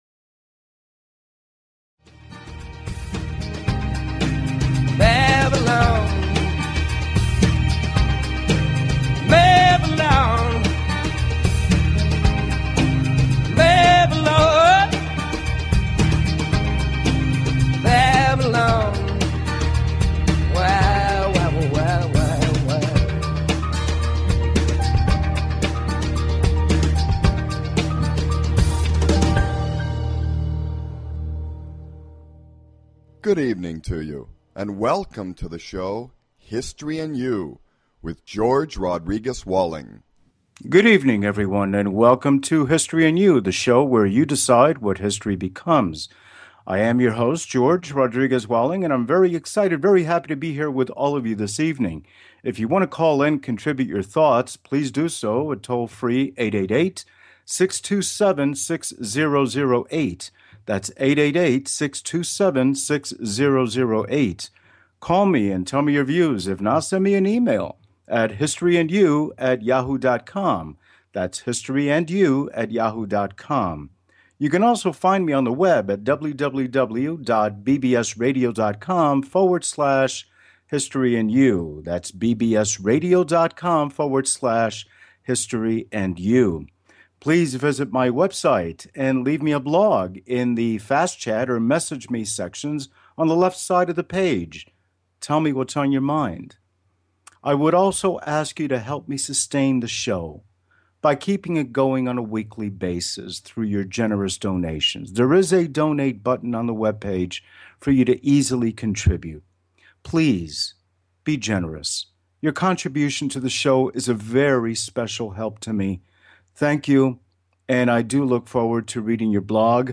This evening, two young artists have given us a moment to stop, and to not pay attention to time, because it doesn’t matter; for they have reminded us through what they do, that the hope of all art lies in the promise to always enchant; it is nestled within a young heart to charm and make us remember along the way, the beauty of being young, if only for a while.